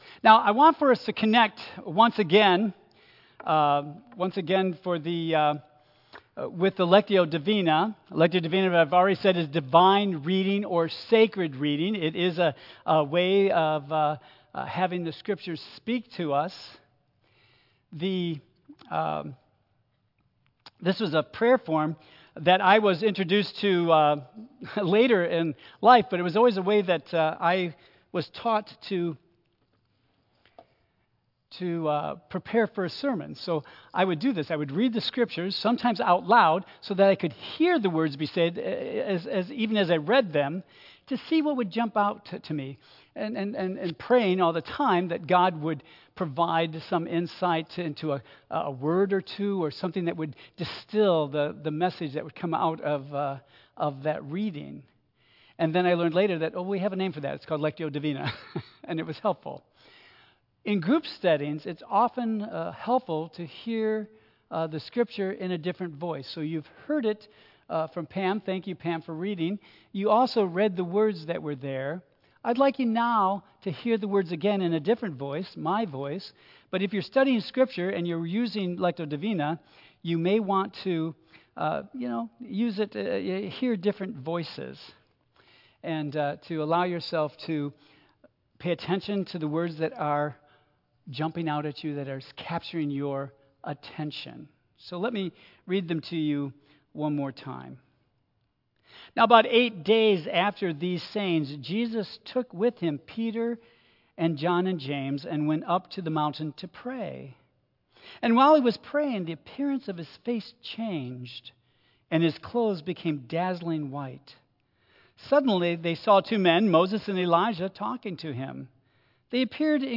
Praying with Jesus Lenten Message Series An important part of prayer is “Holy Listening,” or listening for God.
Tagged with Michigan , Praying , Sermon , Waterford Central United Methodist Church , Worship